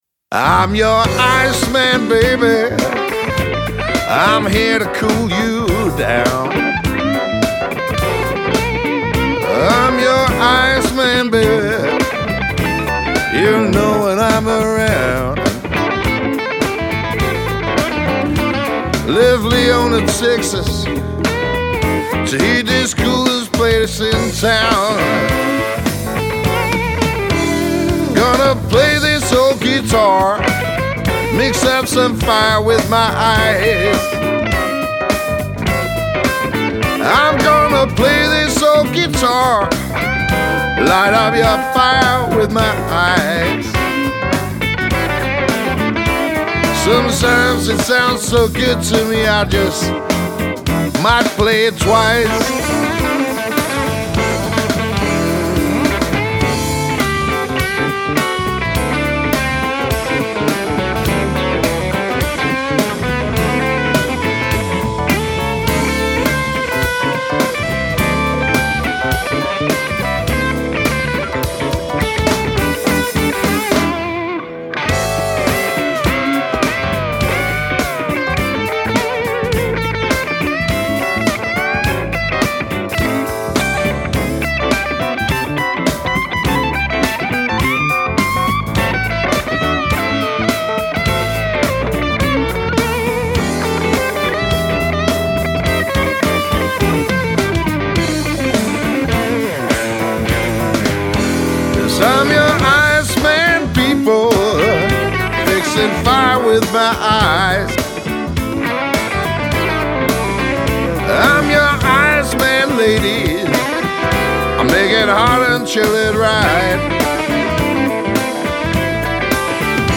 Når festen skal emme af Mississippi og Rock 'n' Roll